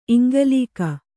♪ iŋgalīaka